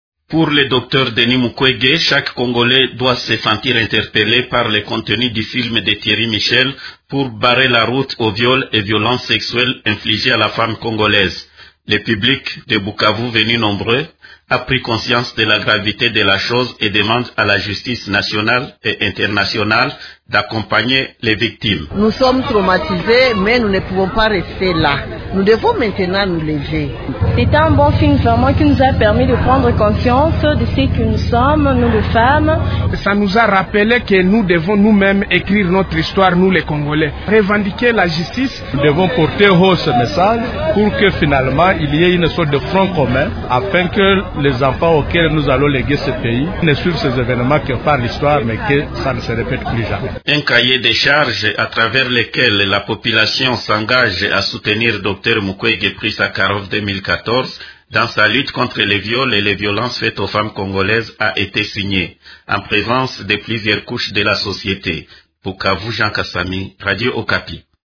D’autres personnes ont souligné la nécessité de porter haut le message véhiculé par le film. Vous pouvez les écouter ici: